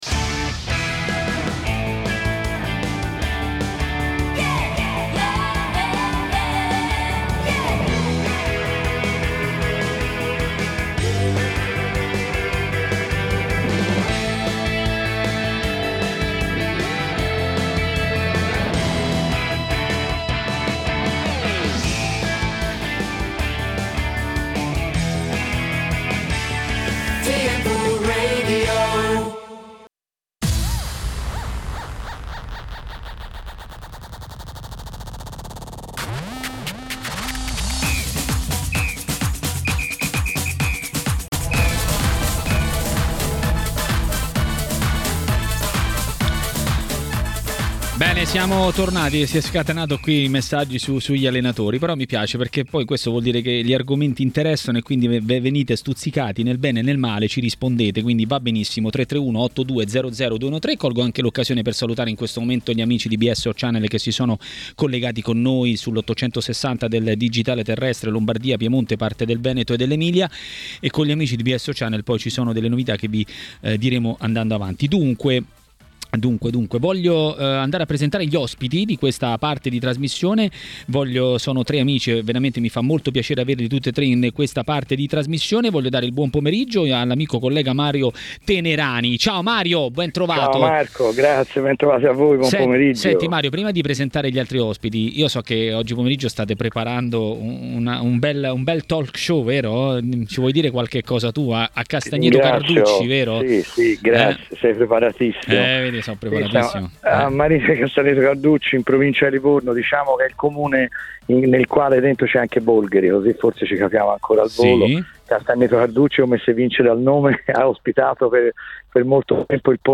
Gianni Di Marzio è intervenuto durante Maracanà sulle frequenze di TMW Radio.